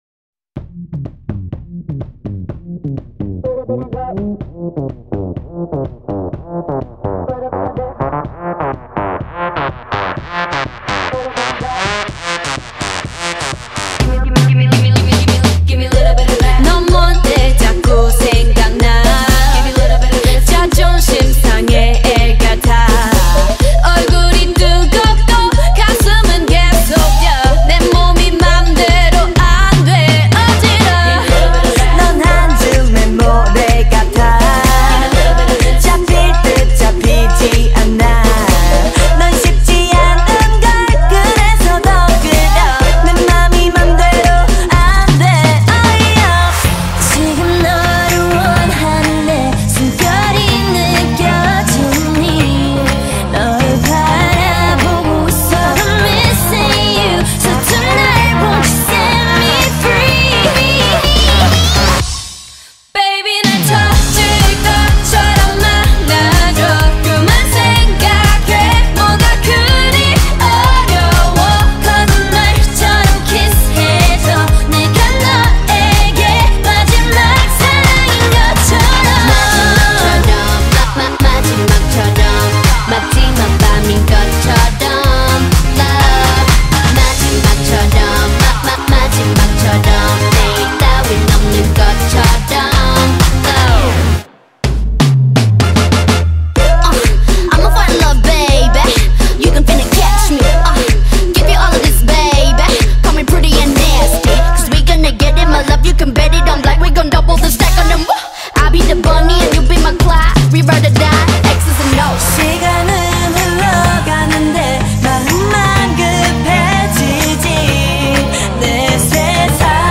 South Korean girl group